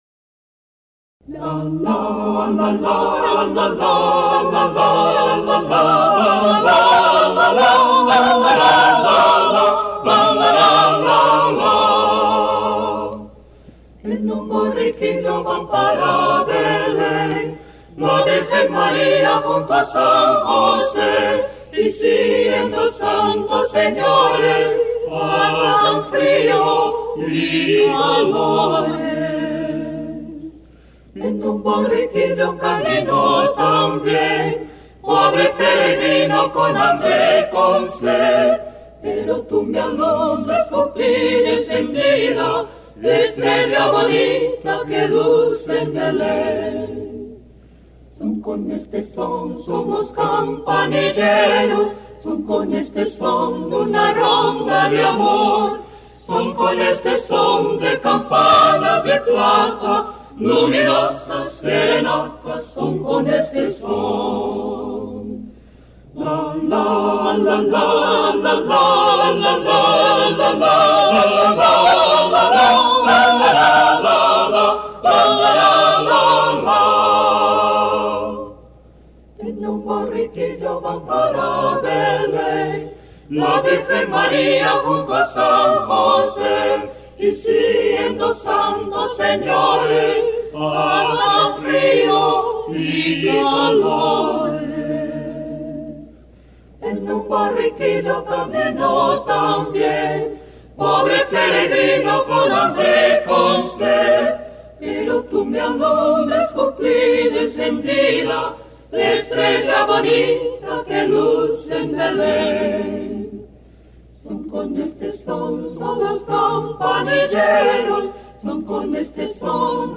Sopranos
Contraltos
Tenores
Baixos
Popular Espanhol